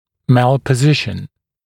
[ˌmælpə’zɪʃn][ˌмэлпэ’зишн]неправильное положение, эктопия, дистопия